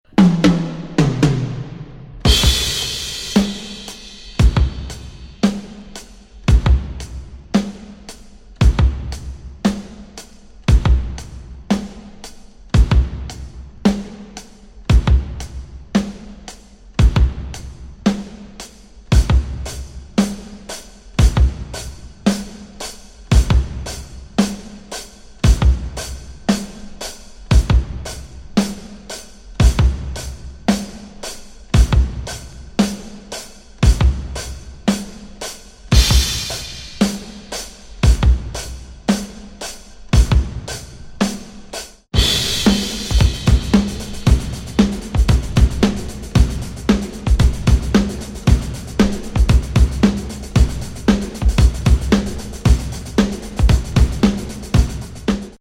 krautrock
Space fusion with electric piano
plus massive (and very long) drumbreaks